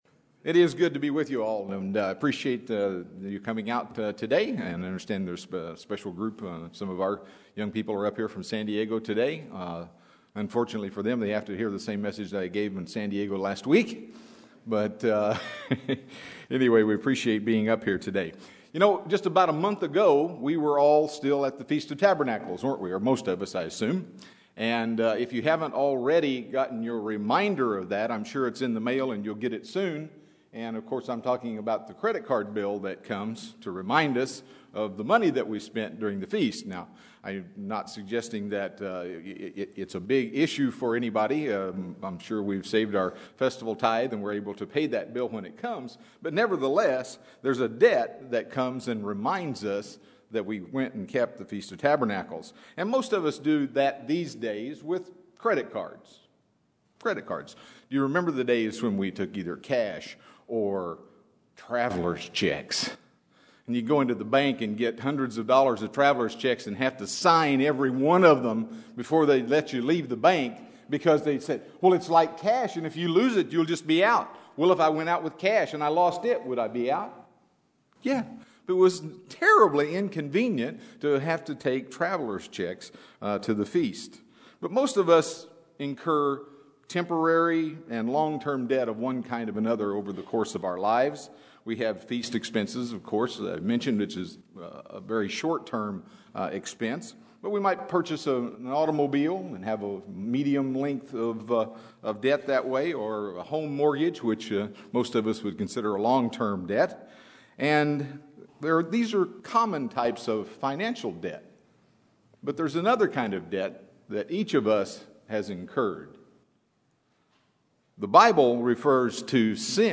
UCG Sermon Studying the bible?
Given in Los Angeles, CA